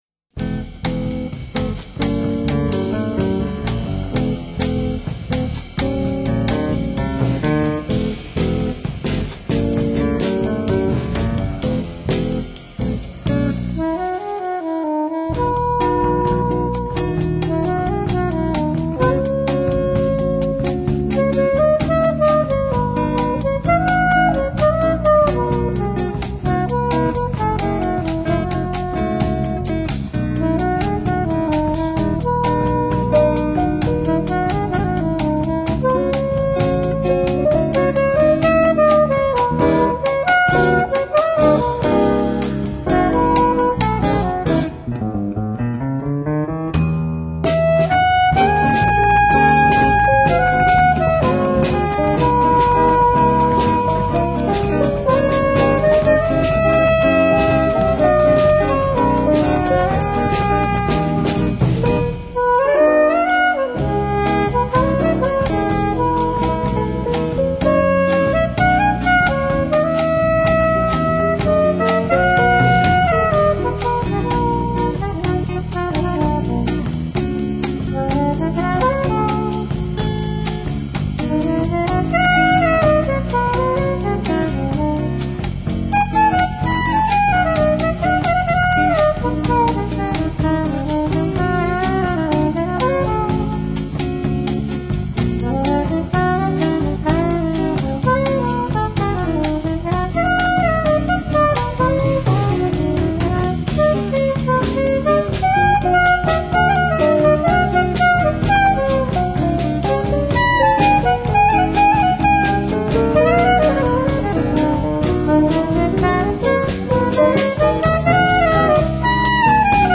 保证拉丁味！
正宗拉丁编曲：月夜愁、茉莉花、高山青.....